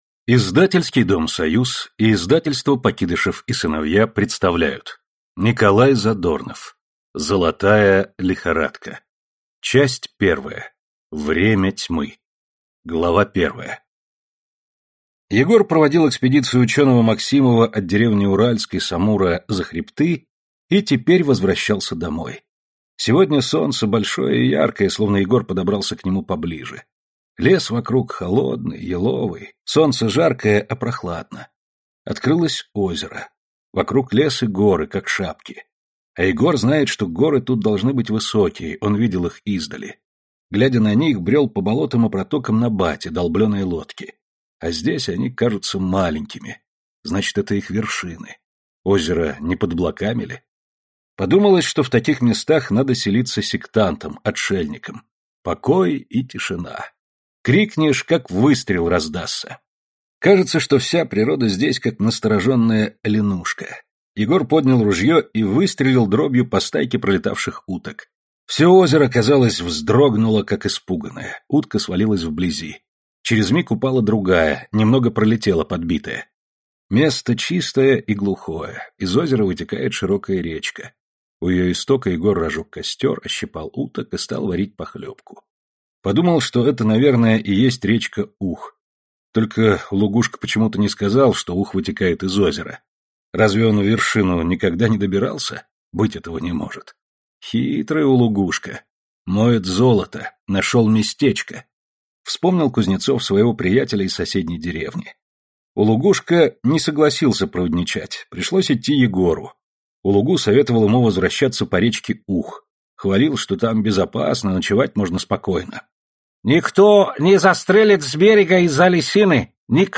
Аудиокнига Золотая лихорадка | Библиотека аудиокниг